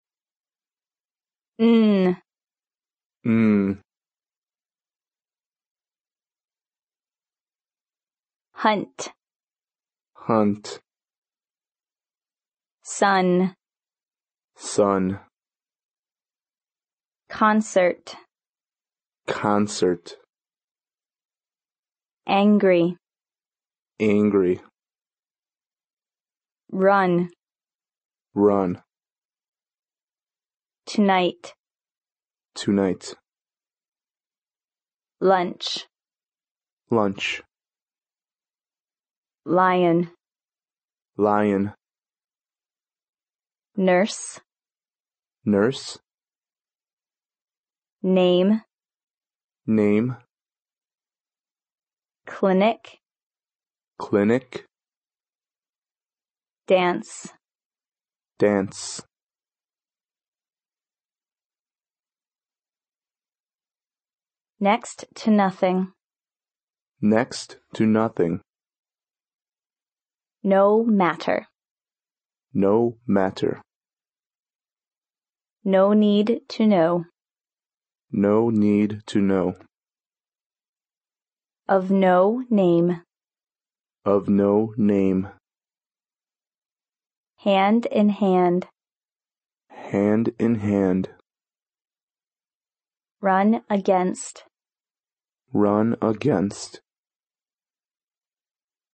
英语国际音标：鼻音[n] 听力文件下载—在线英语听力室